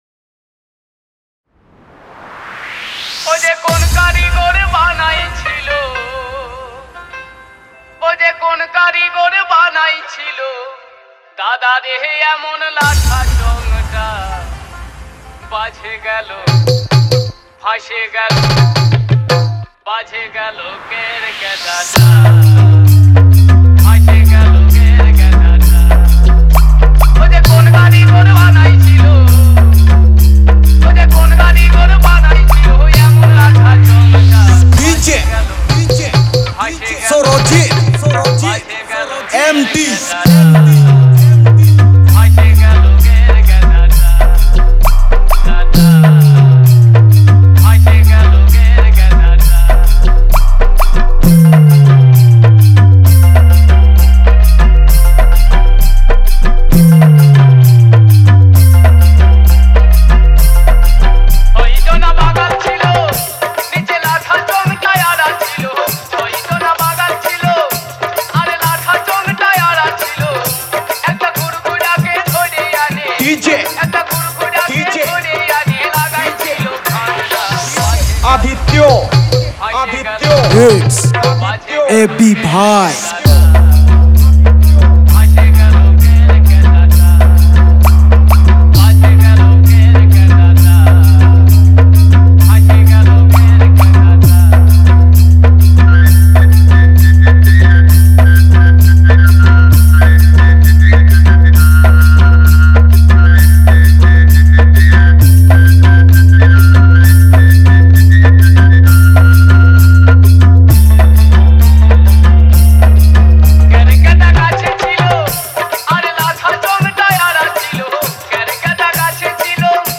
Bhojpuri dj